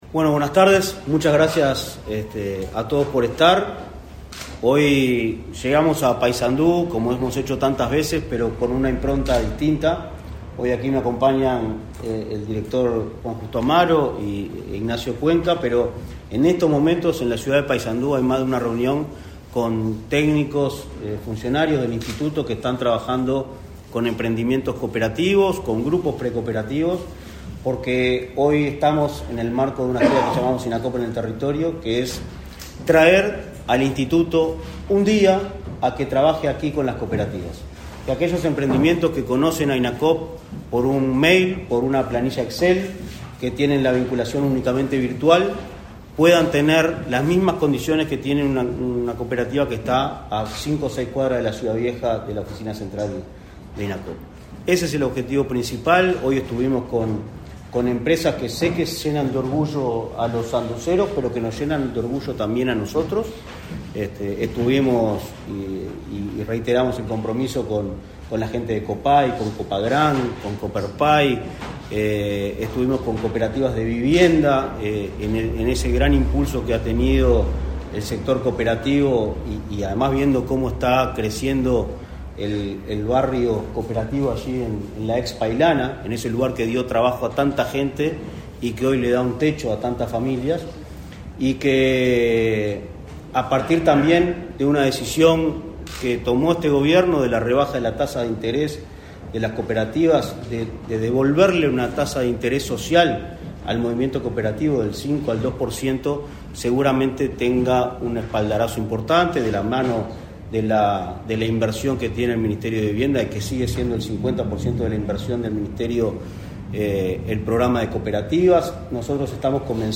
Conferencia de prensa del presidente del Inacoop, Martín Fernández
Conferencia de prensa del presidente del Inacoop, Martín Fernández 27/09/2023 Compartir Facebook X Copiar enlace WhatsApp LinkedIn Autoridades del Instituto Nacional del Cooperativismo (Inacoop) realizaron, este 27 de setiembre, una recorrida por el departamento de Paysandú, donde se reunieron con el intendente departamental. Tras el encuentro, el presidente del Inacoop, Martín Fernández, realizó una conferencia de prensa.